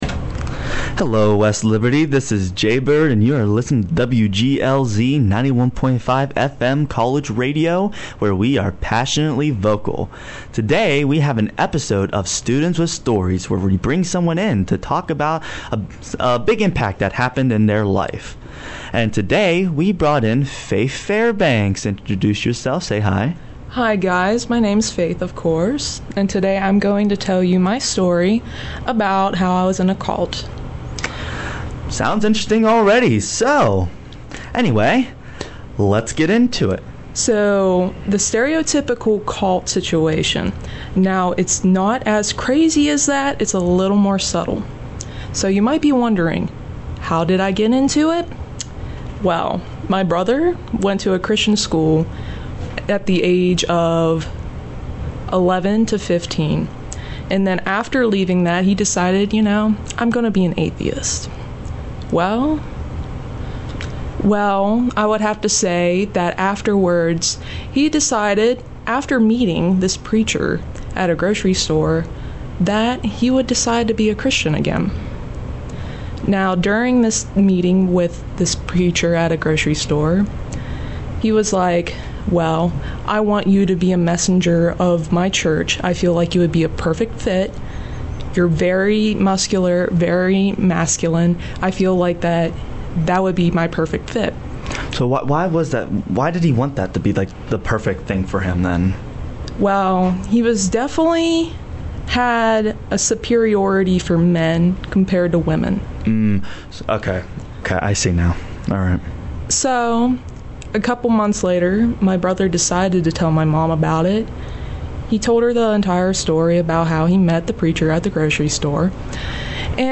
interviewing